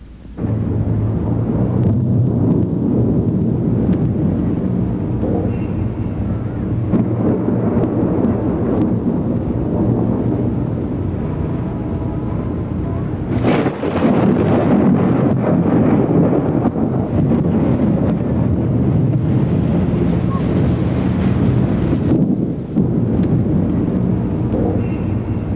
Thunder Sound Effect
Download a high-quality thunder sound effect.
thunder-3.mp3